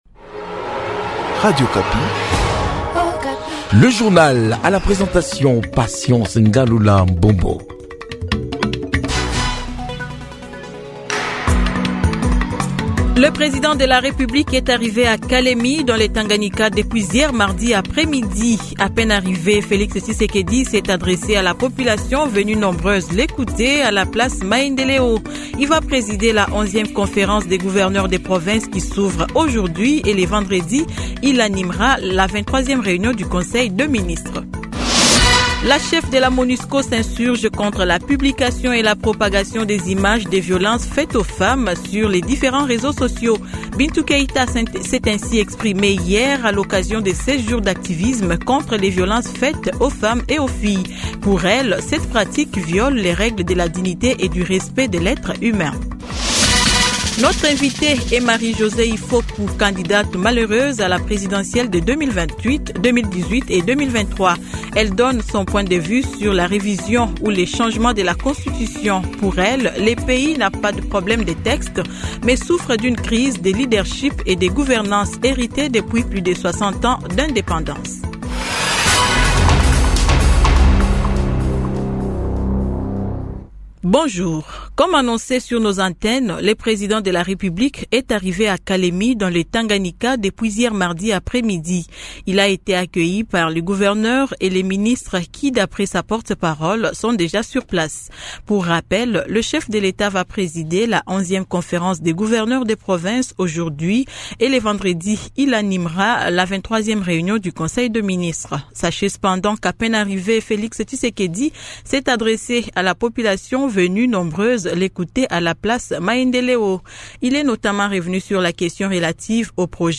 Journal matin 06H-07H